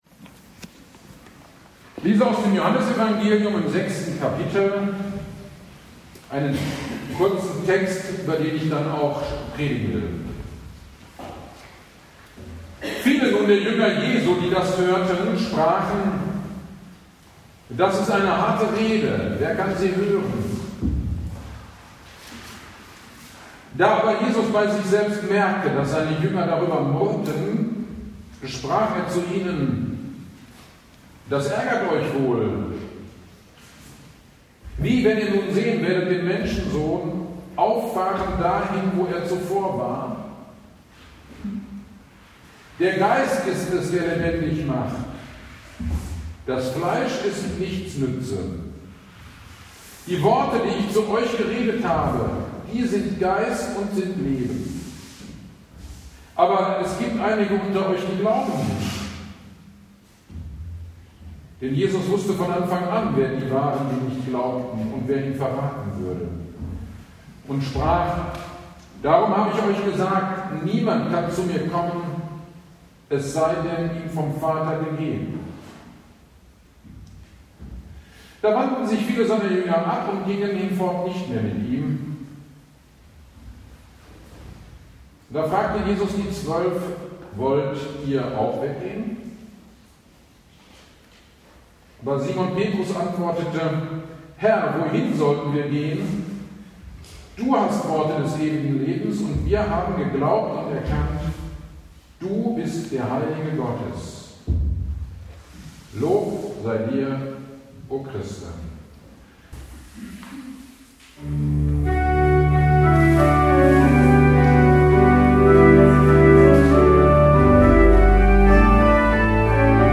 GD am Reformationstag 2024 Predigt zu Johannes 6,60-69